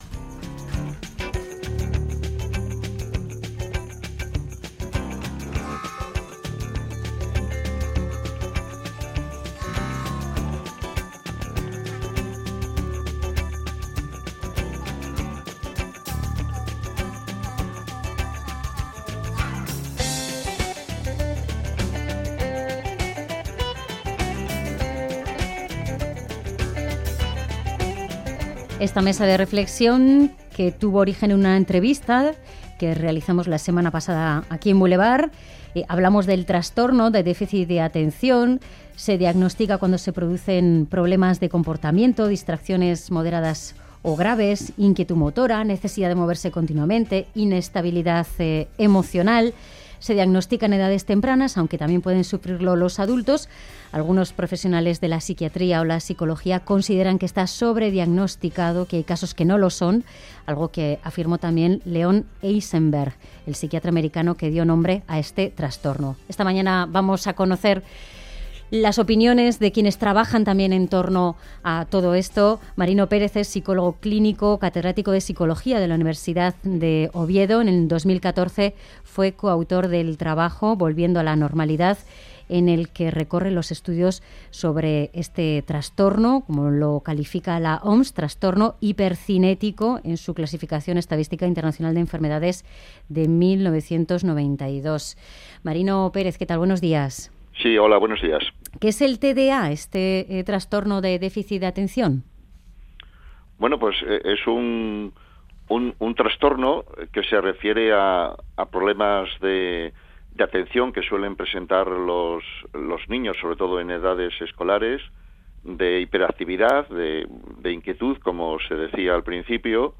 Nuestra mesa de reflexión ha girado en torno al TDAH (Trastorno por Déficit de Atención e Hiperactividad) EUSARGHI Clínica Navarra